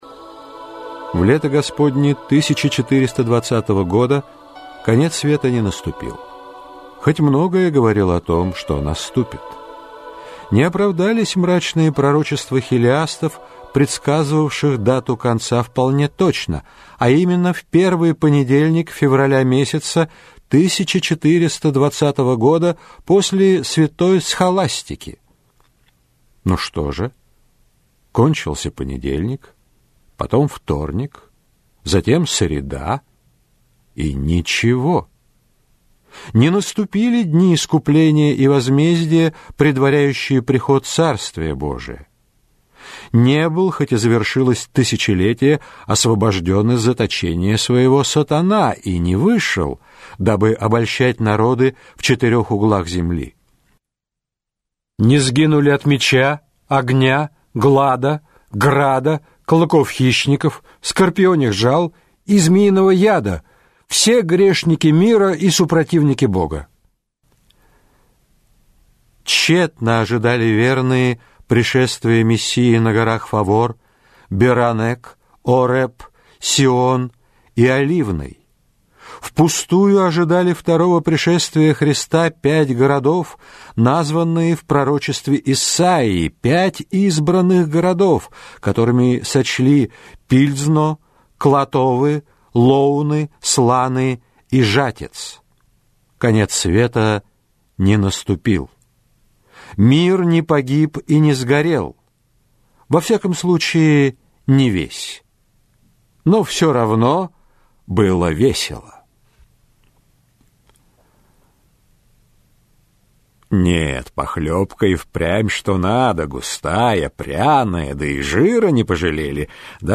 Аудиокнига Башня шутов - купить, скачать и слушать онлайн | КнигоПоиск